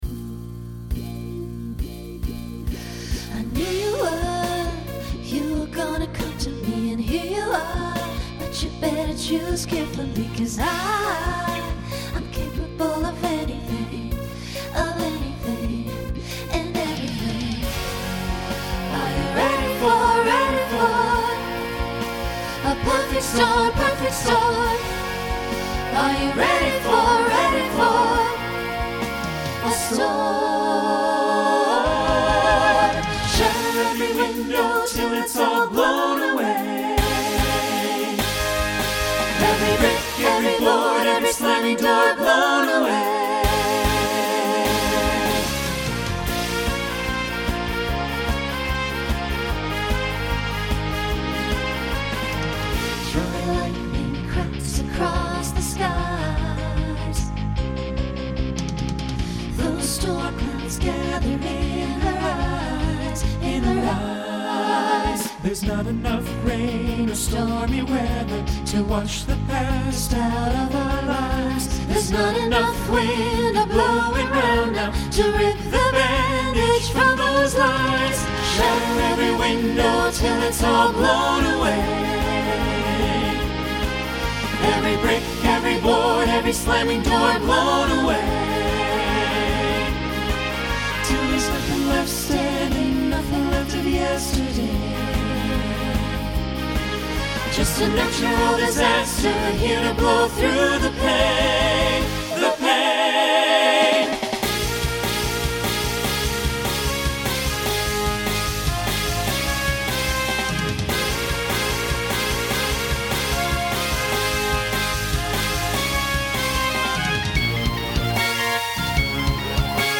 New SSA voicing for 2026.